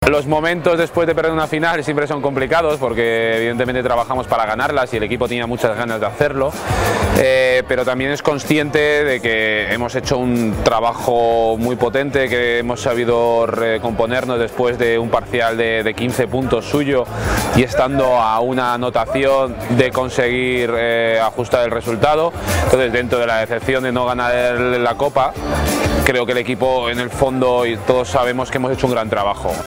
con un sabor agridulce aún en los labios formato MP3 audio(0,57 MB